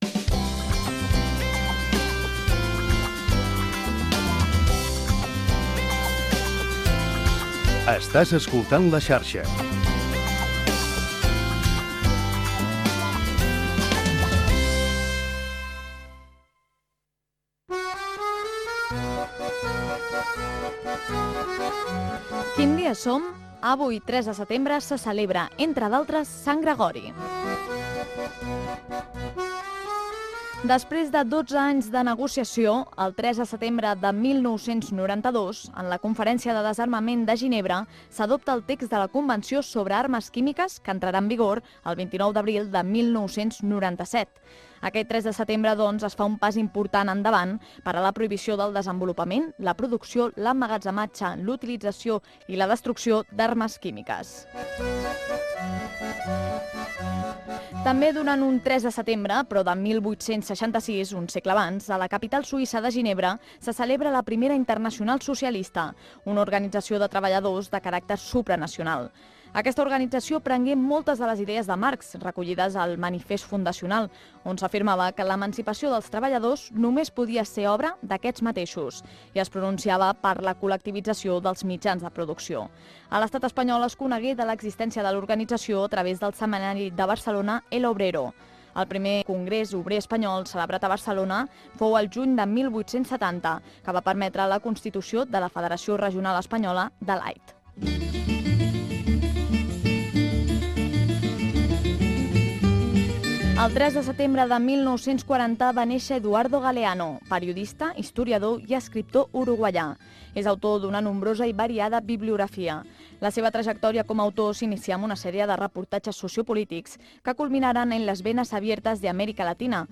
Indicatiu de l'emissora. Desconnexió. Santoral, efemèrides
Informatiu